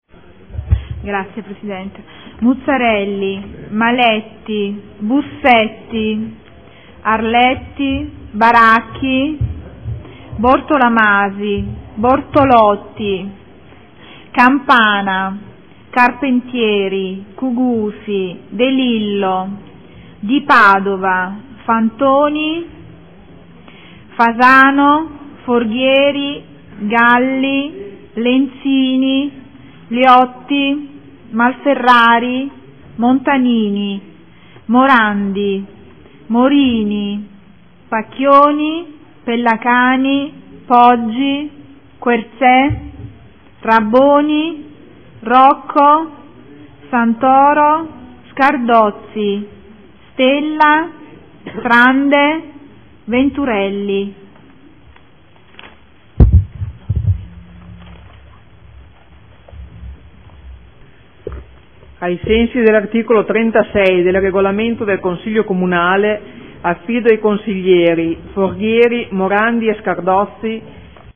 Segretaria — Sito Audio Consiglio Comunale
Seduta del 09/03/2015 Appello.